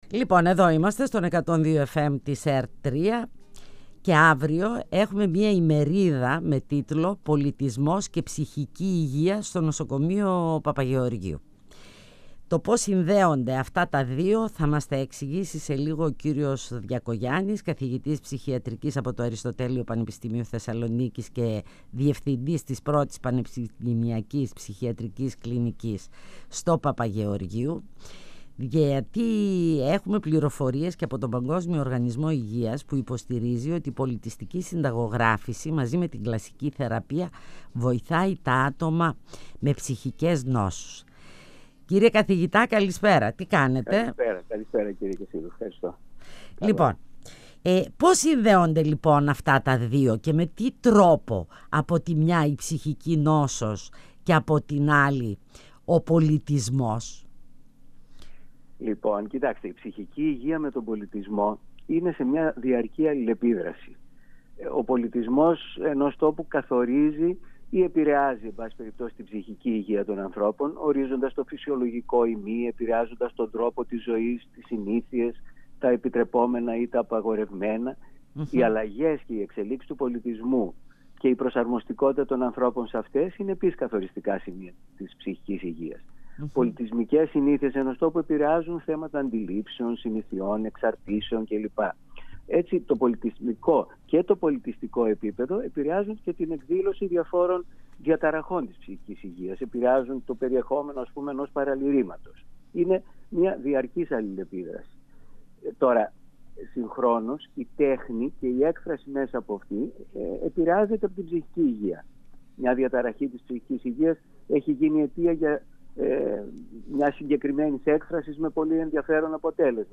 102FM Φωνες Πισω απο τη Μασκα Συνεντεύξεις